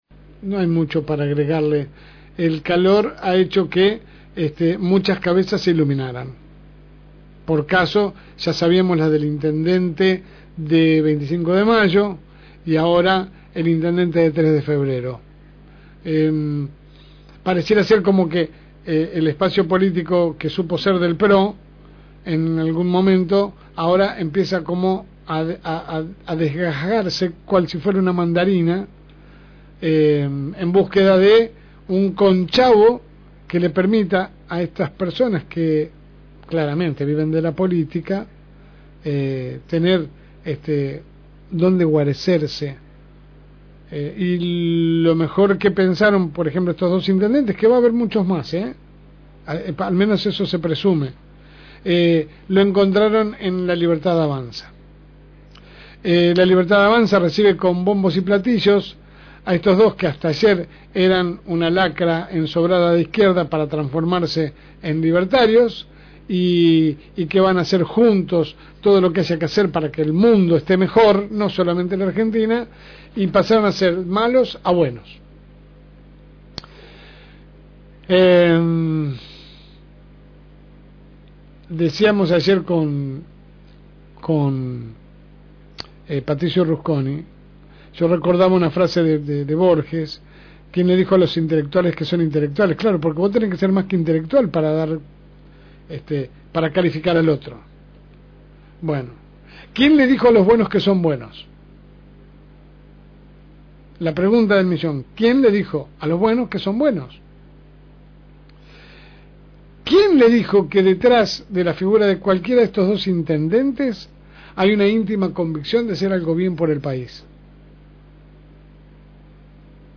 AUDIO – Editorial de la LSM. – FM Reencuentro